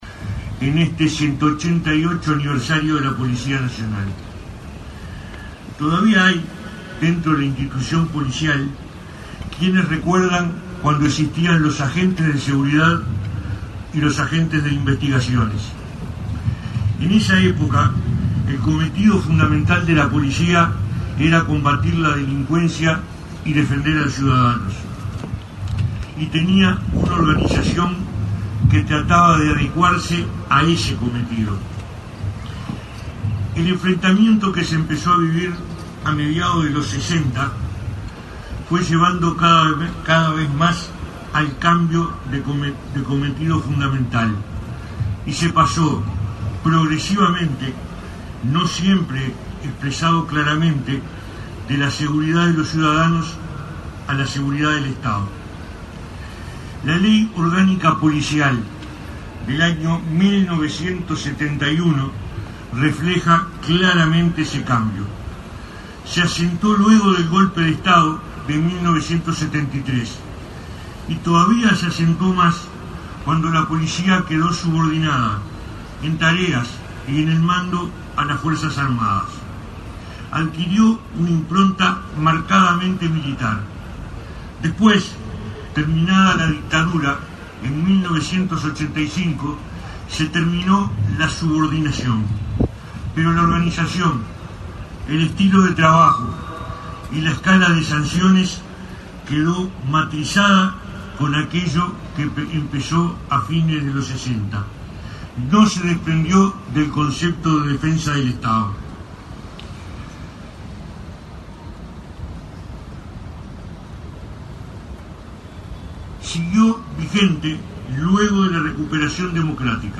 En el 188.° aniversario de la Policía Nacional, el ministro del Interior, Eduardo Bonomi, destacó los cambios realizados en la última década, que permitieron contar con una Policía más profesional y mejor aceptada por los ciudadanos. Innovaciones tecnológicas, mayor formación y una visión de proceso son algunos de los factores de la reforma de la Policía para bajar las cifras de criminalidad.